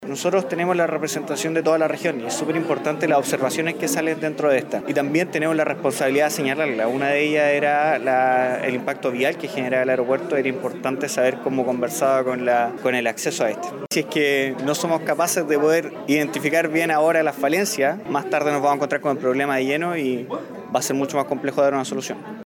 Al respecto, el consejero regional y presidente de la Comisión de Medioambiente, Felipe Córdova, señaló que una de las observaciones realizadas al proyecto tiene que ver con el impacto vial que generaría su construcción en el sector.